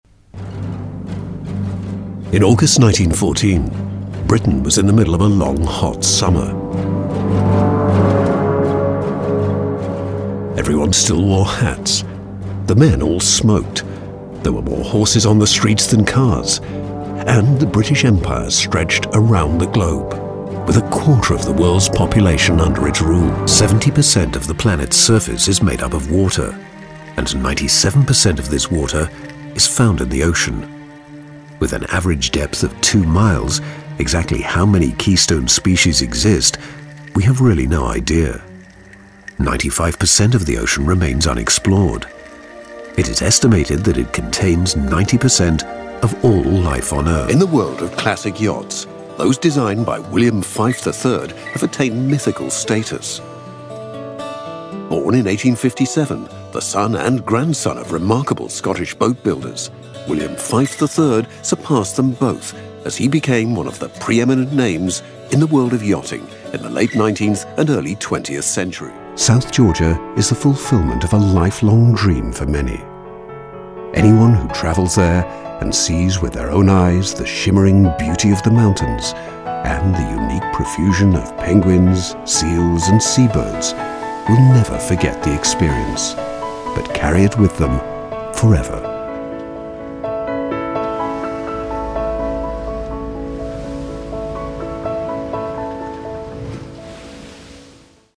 Documentary demo
Contemporary British English; English RP; London; Yorkshire; Liverpool; Scottish; West Country; Various European; Standard American
Middle Aged